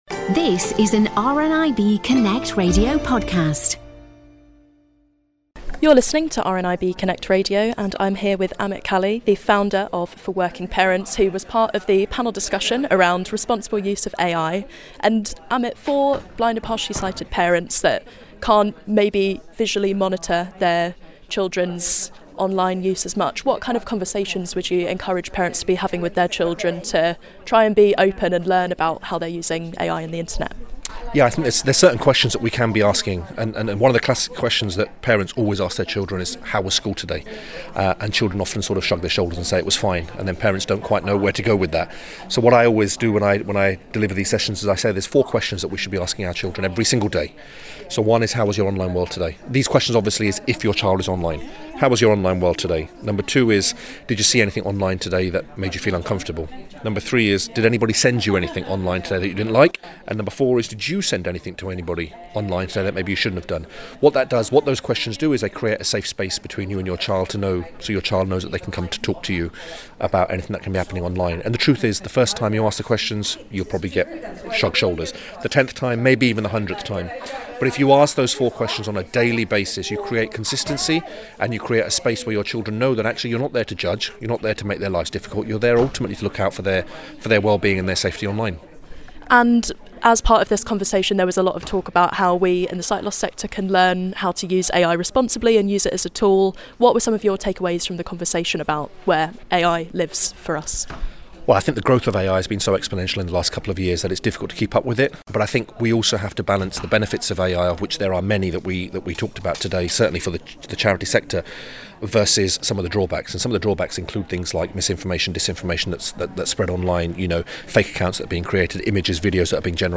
The Visionary Annual Conference took place in Birmingham this week, where local and national sight loss organisations came together to share ideas for better supporting blind and partially sighted people.